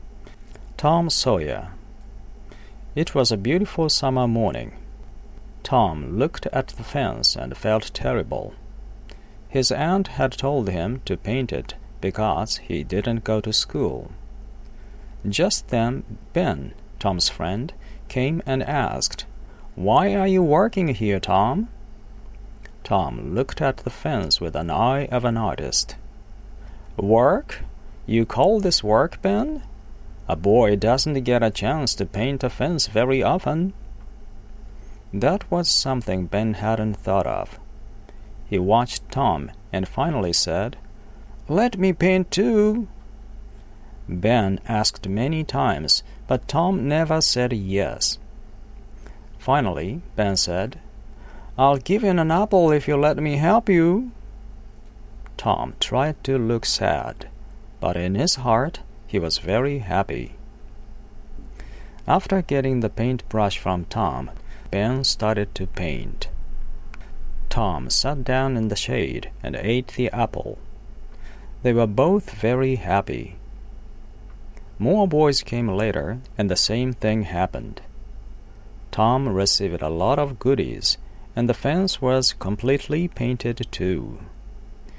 モデルリーディングは↓こちら